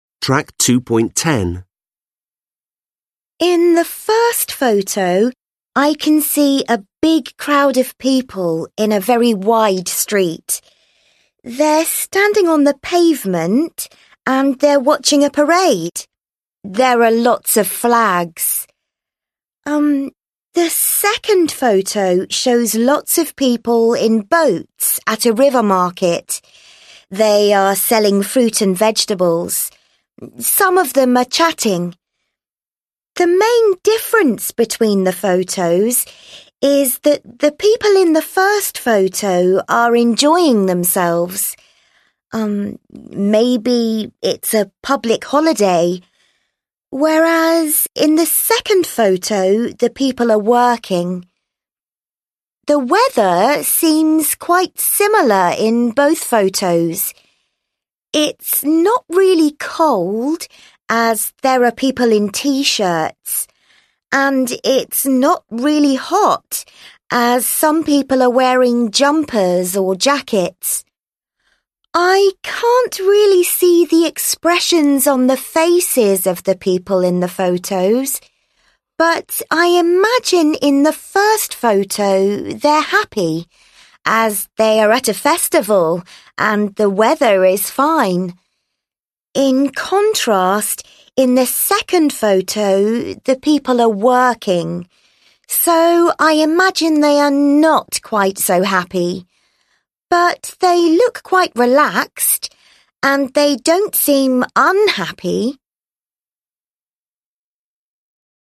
6 (SGK trang 54 sách Tiếng Anh 10 Friends Global) Listen to the second candidate again.